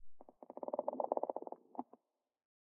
creaking_heart_idle3.ogg